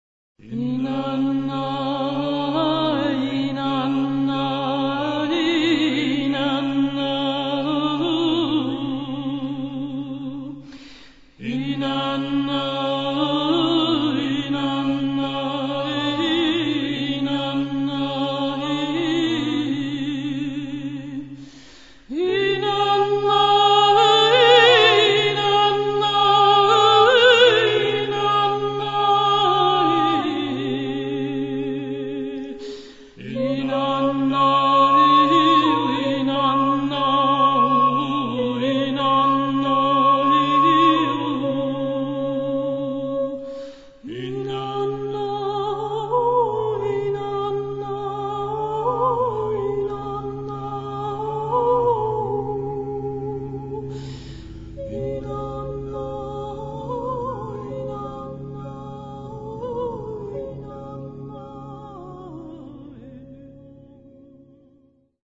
Konzertharfe und keltische Harfe